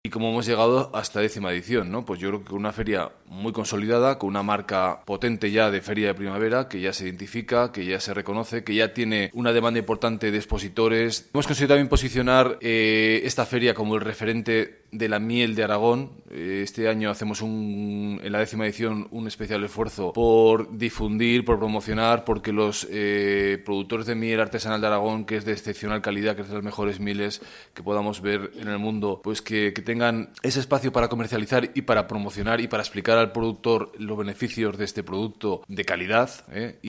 Audio del alcalde de Biescas, Luis Estaún:
DPH_Luis-Estaun_FeriaPrimaveraBiescas_mayo2019.mp3